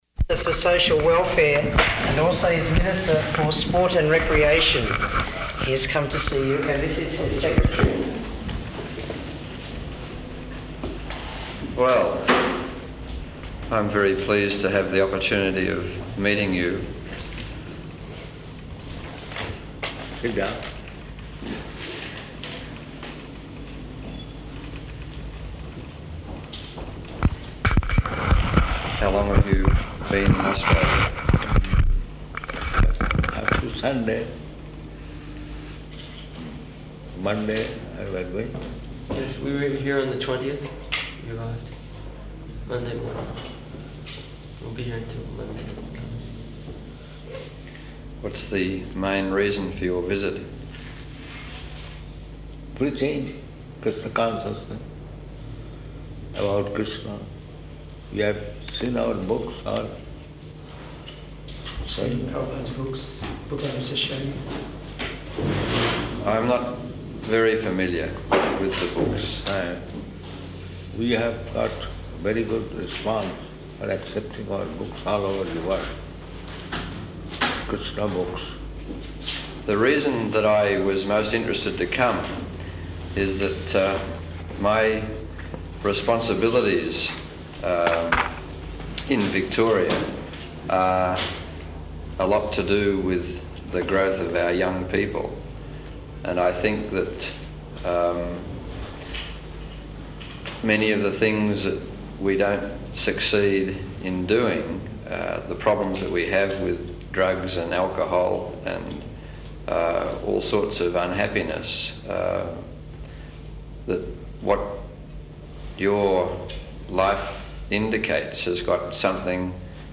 Room Conversation with Minister Dixon, State Minister for Social Services